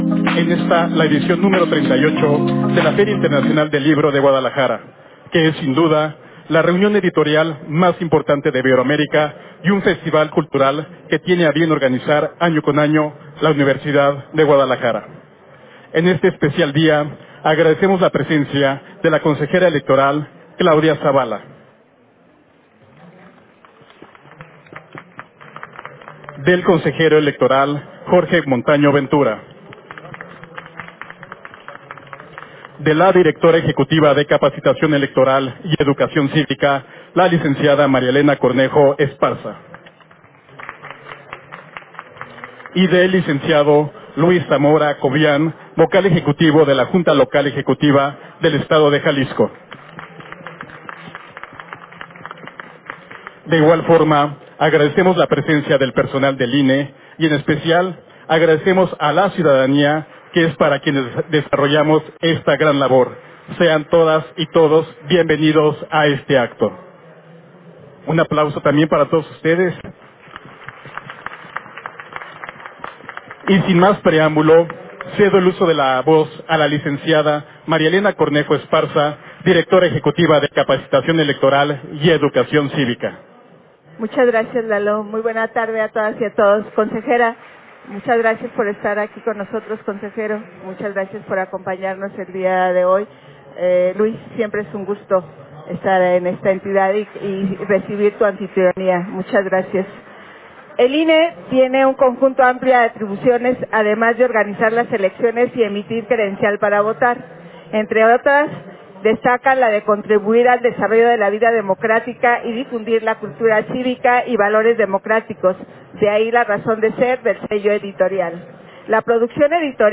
INAUGURACION DEL STAND DEL INE FIL GUADALAJARA 2024